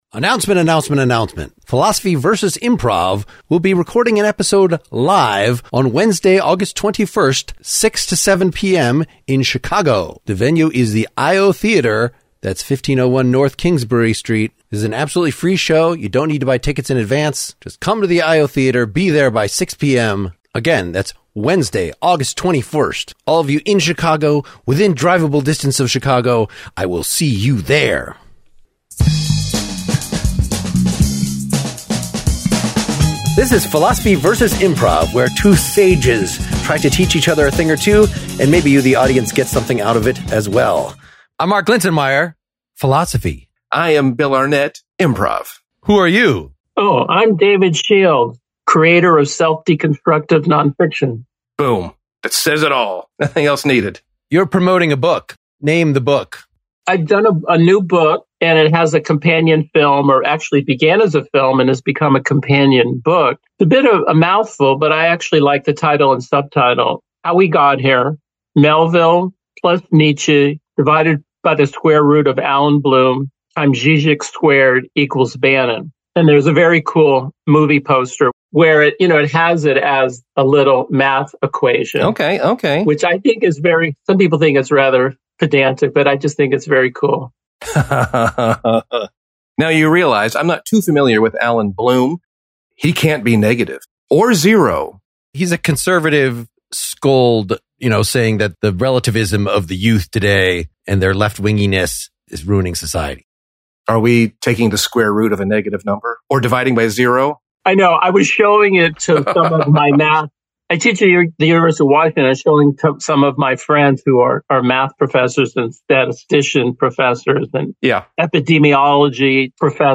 Internationally best-selling author David wrote a book (and made a film) called How We Got Here, which traces the gradual path in the history of ideas from the ancients through various forms of perspectivism, relativism, and post-modernism to the post-truth discourse that authoritarians and wanna-be authoritarians engage in. Some improv scenes are inserted awkwardly into the discussion.